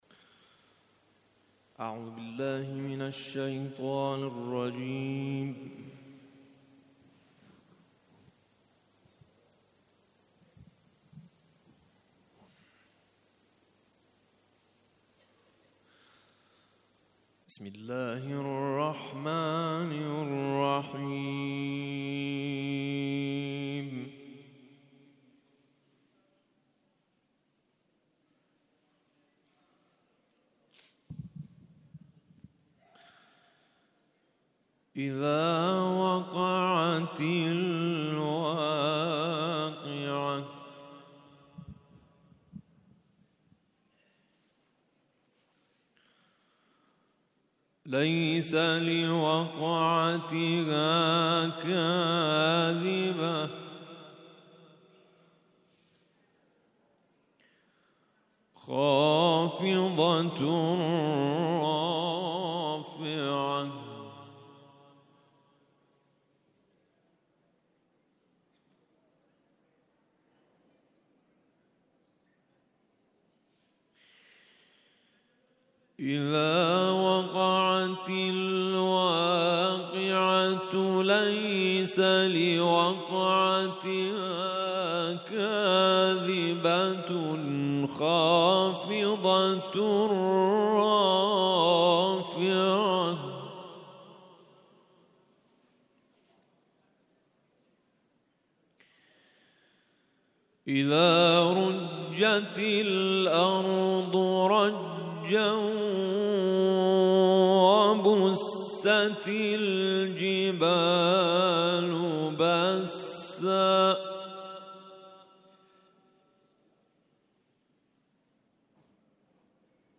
تلاوت قرآن کریم